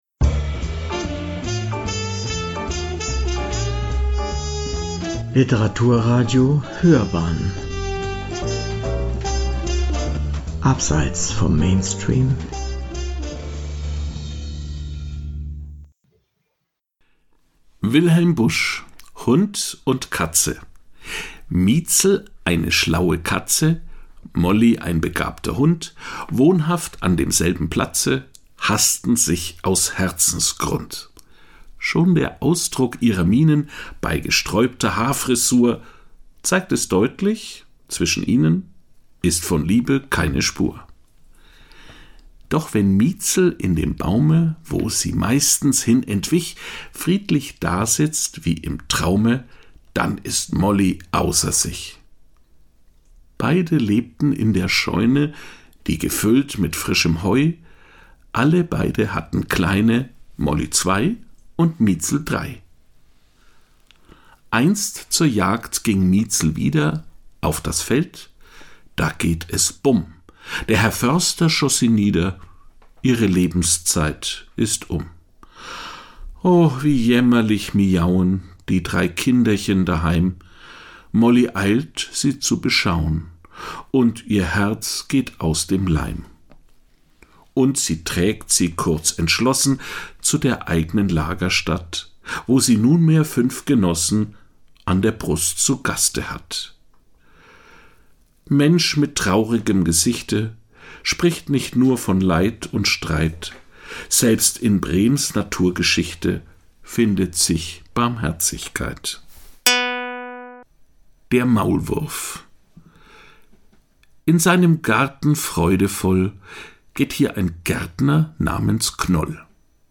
Er war einer der einflussreichsten humoristischen Dichter und Zeichner Deutschlands. Zwischen 1862 und 1888 hielt er sich oft in Wolfenbüttel auf, der Heimatstadt eines der Sprecher, was letzterem von Kindheit an ein besonderes Verhältnis zum Werk Wilhelm Buschs ermöglichte.